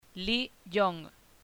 Lee YONGLi Yong